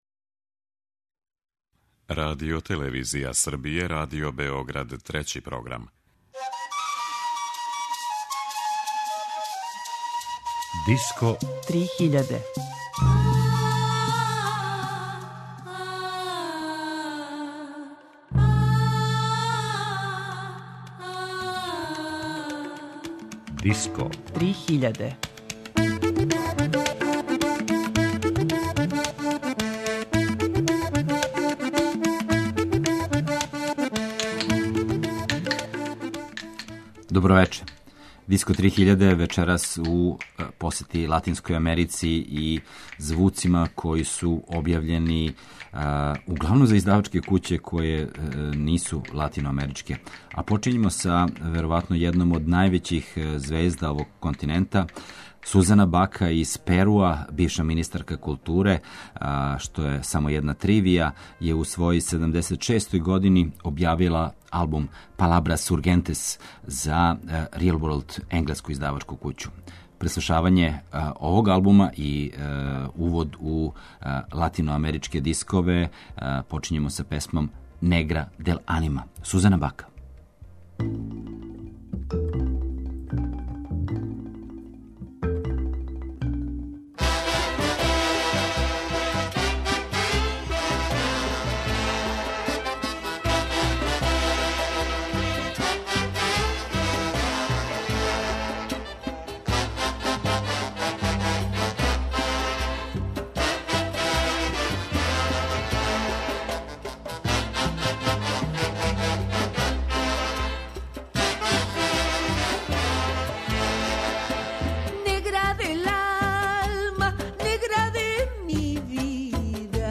world music
бразилски карневалски састав